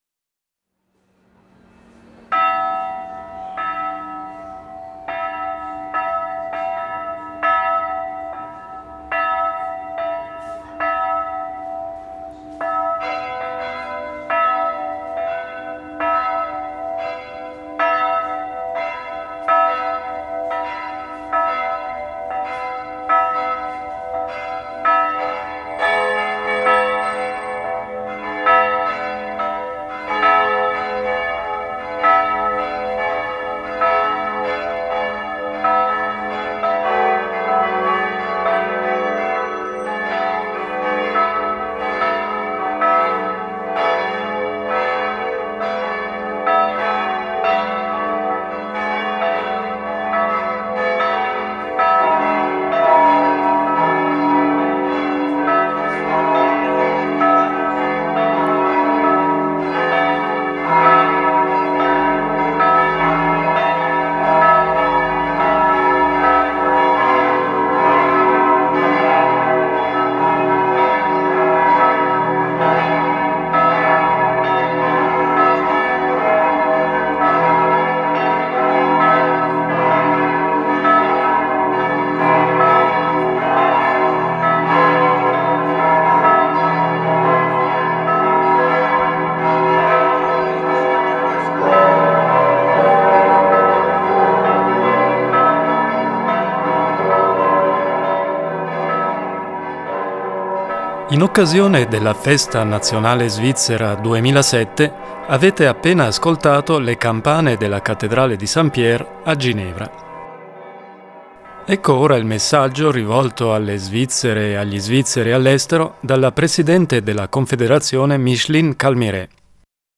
Messaggio della presidente della Confederazione Micheline Calmy-Rey agli Svizzeri all’estero in occasione della Festa nazionale.